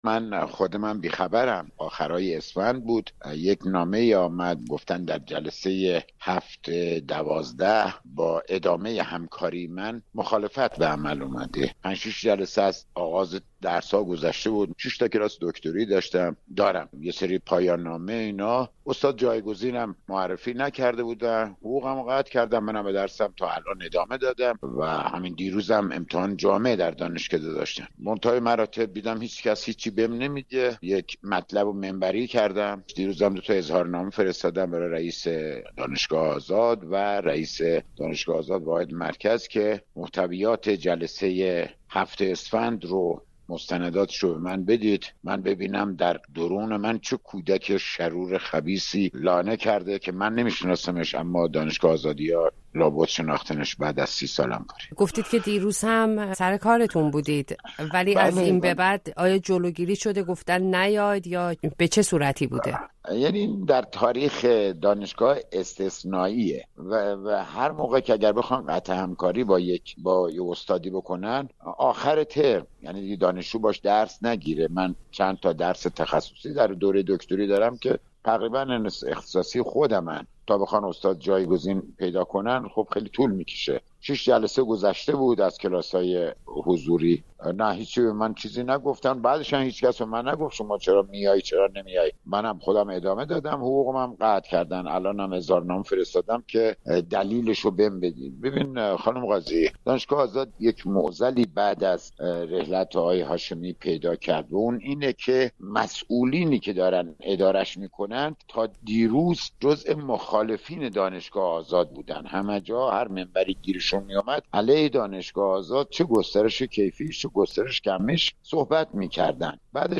در گفتگو با رادیو فردا